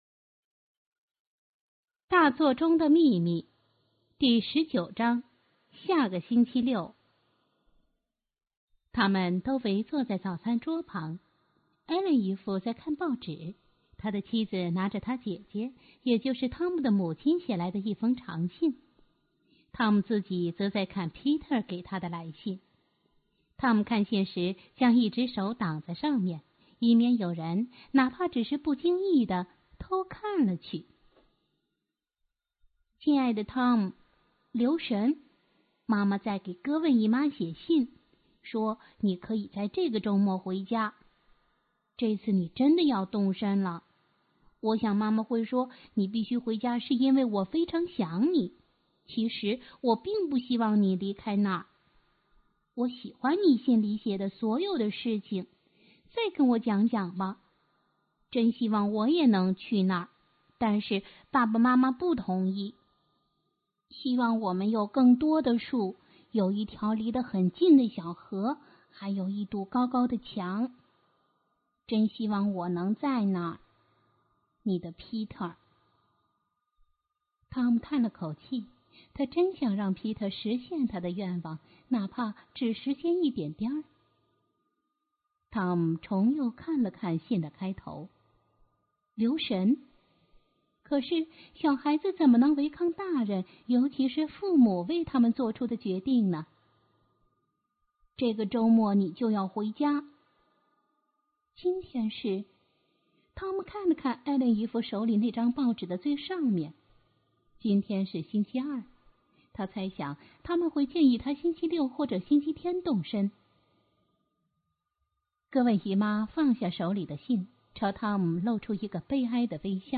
经典故事,听故事,mp3音频故事会